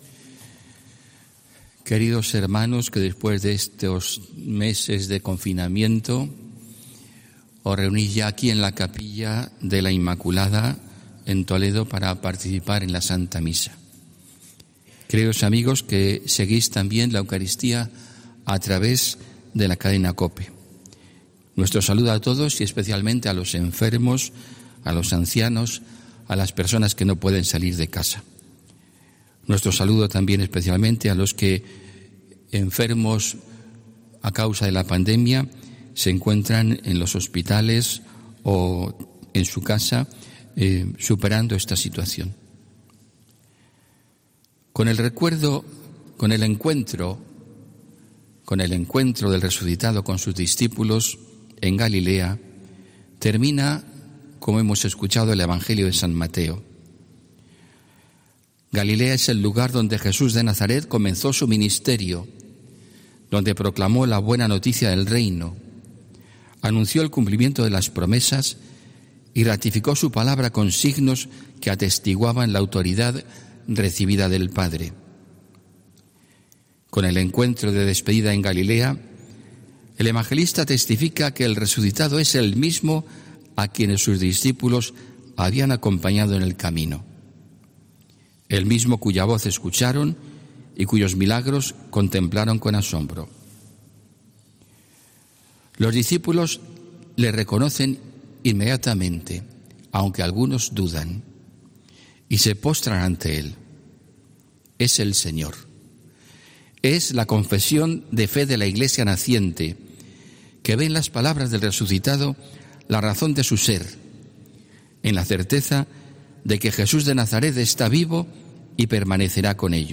HOMILÍA 24 MAYO 2020